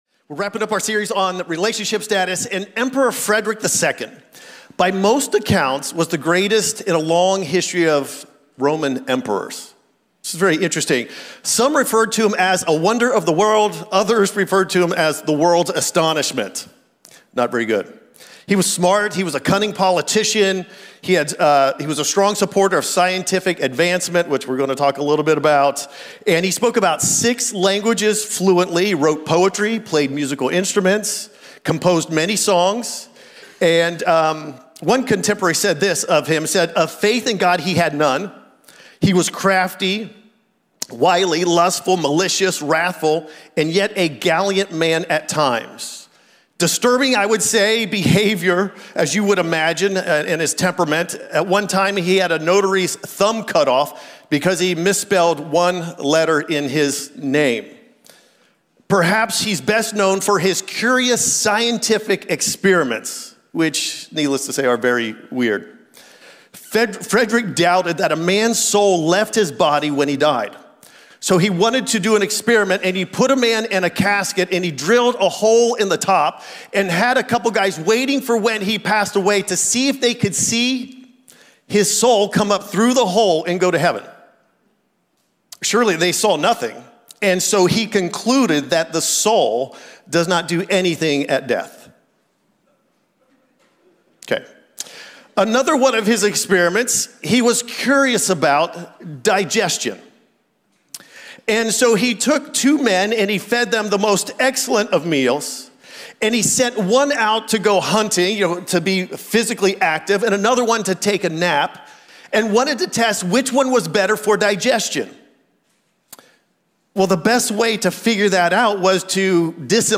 This podcast is dedicated to providing audio for Cornerstone messages and sermon series at the Americus Campus.